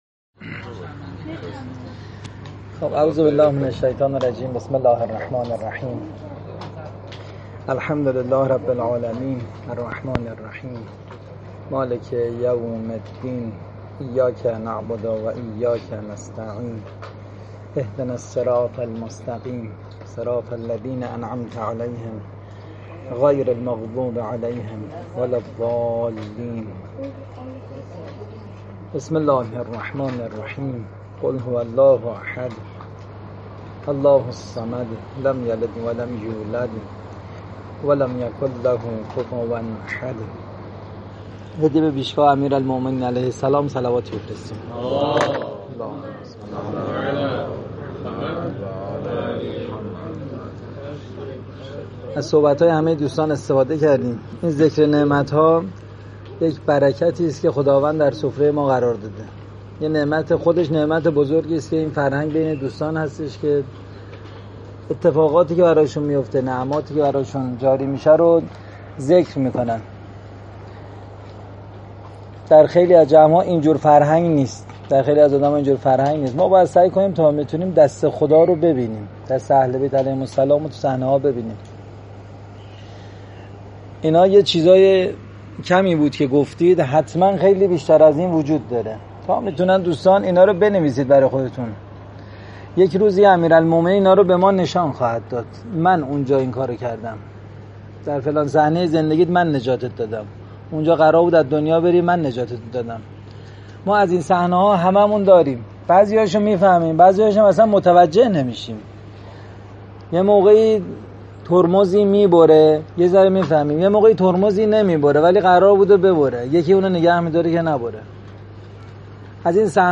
سخنرانی
دوره های معارفی تمسک اربعین 1402